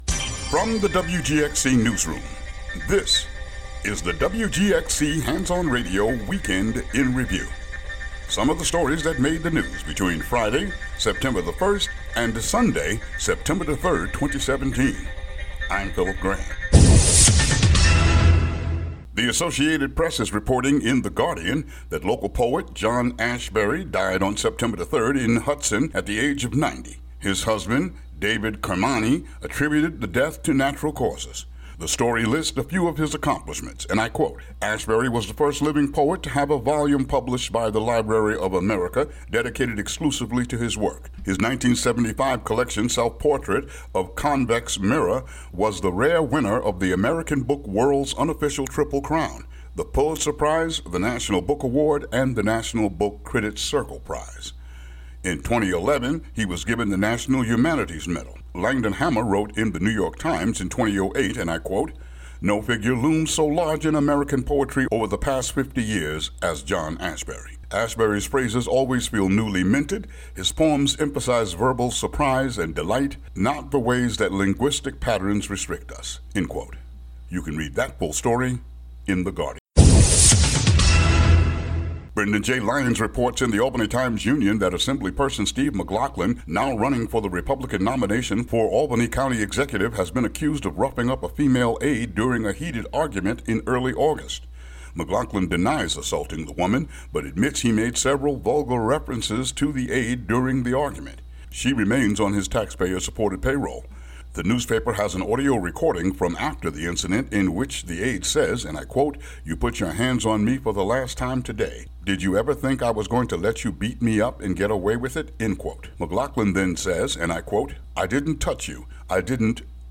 WGXC Local News